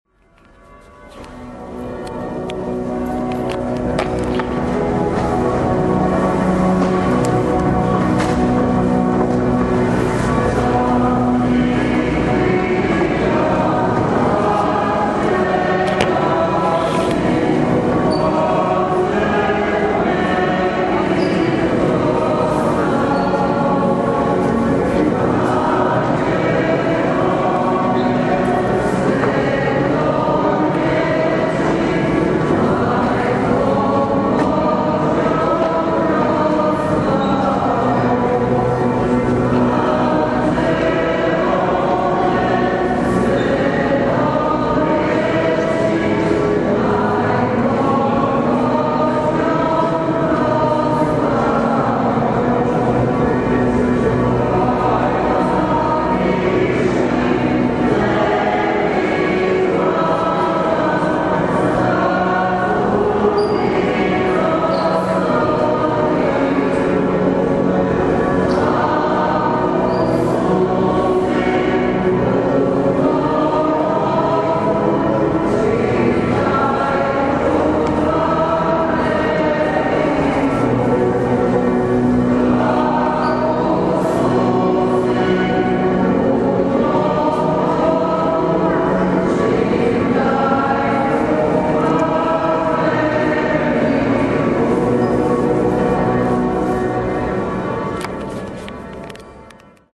ŽUPNI ZBOR – AUDIO:
završna pjesma – ŽUPNI ZBOR